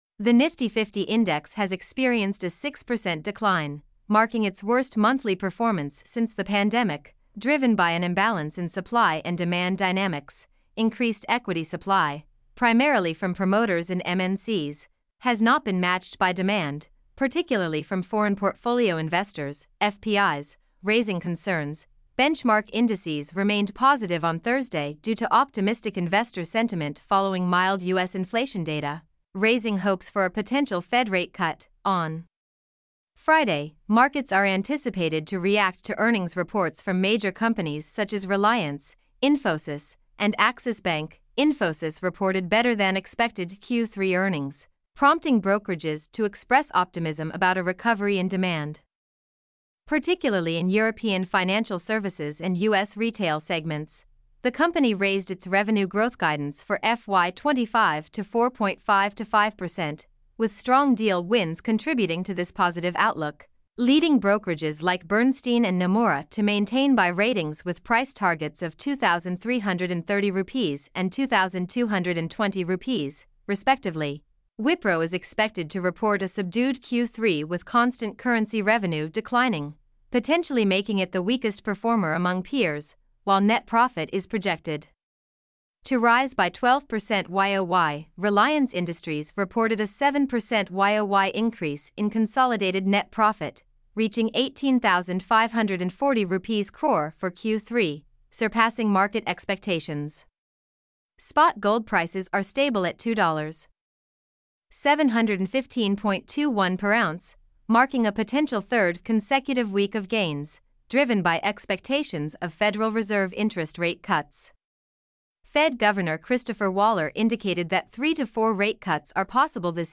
mp3-output-ttsfreedotcom6.mp3